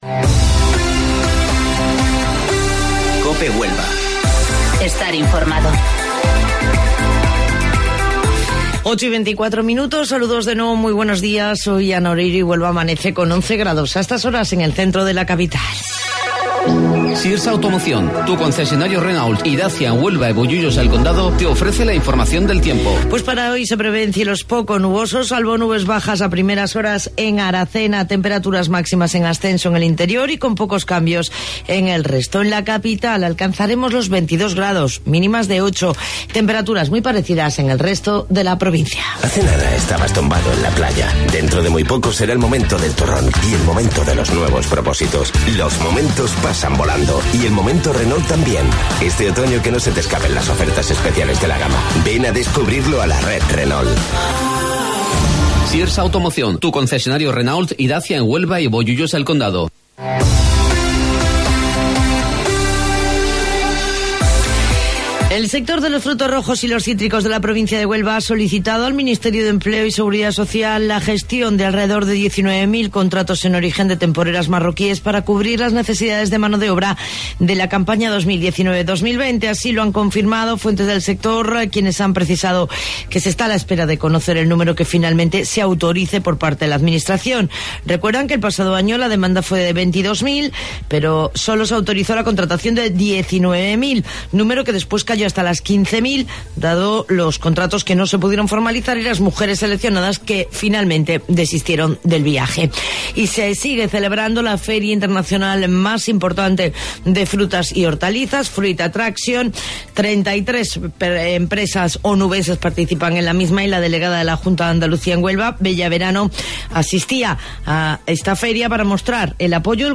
AUDIO: Informativo Local 08:25 del 24 de Octubre